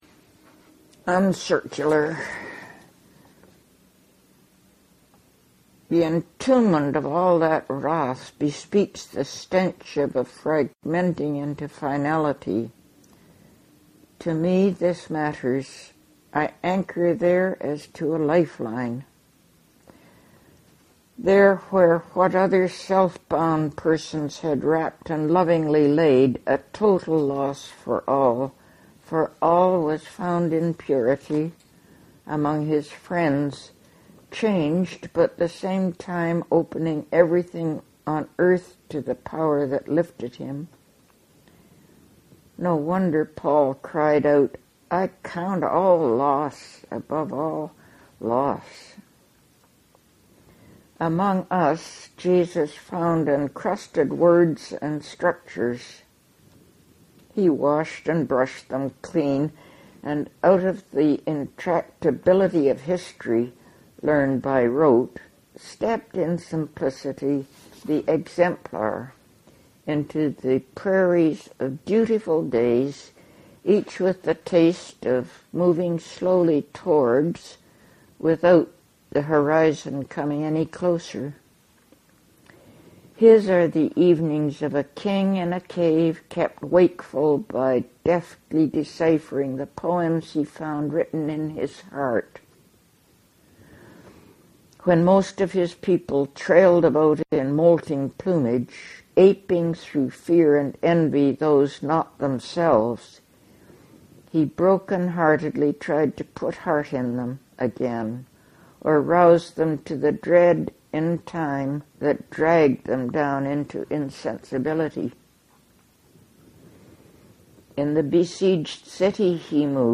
Margaret Avison reads Uncircular from Concrete and Wild Carrot